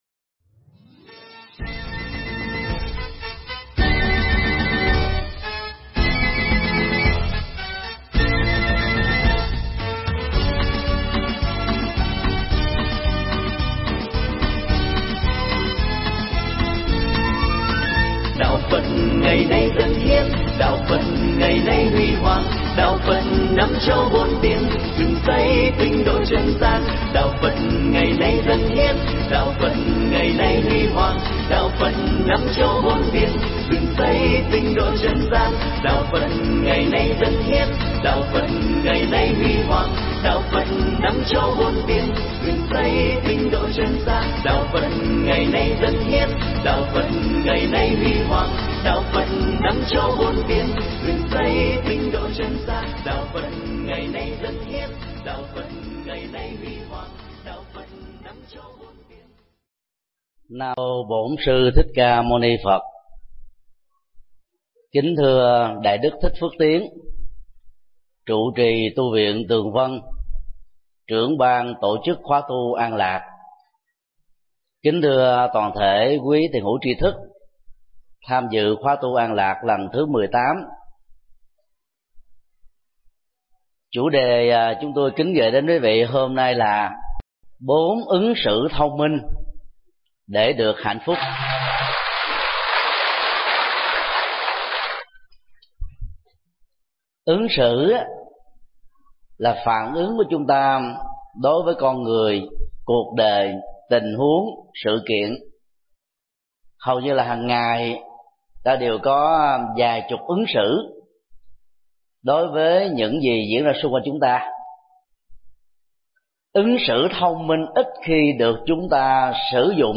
Thuyết pháp Bốn Ứng Xử Thông Minh Để Được Hạnh Phúc
Giảng tại tu viện Tường Vân